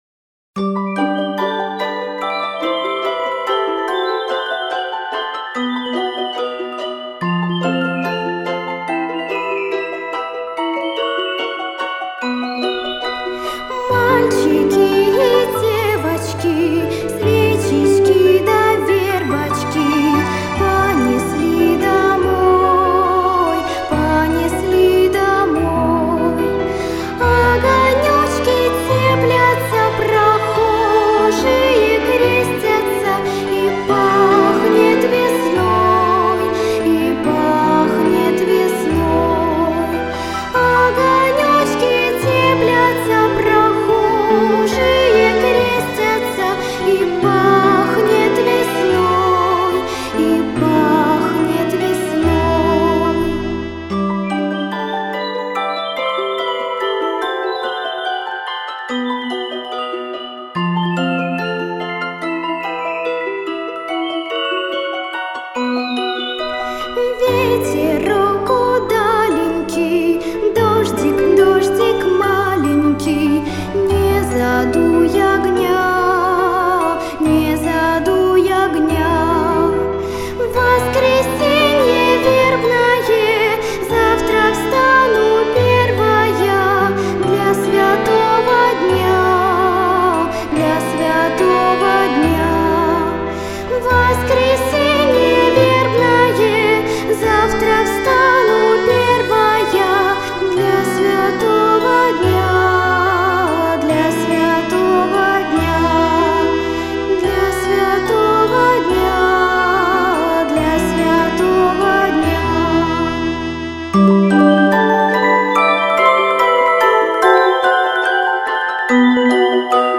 minskiy-detskiy-hor-verbnoe-voskresen-e.mp3